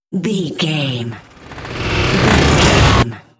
Dramatic riser flashback
Sound Effects
In-crescendo
Atonal
ominous
haunting
eerie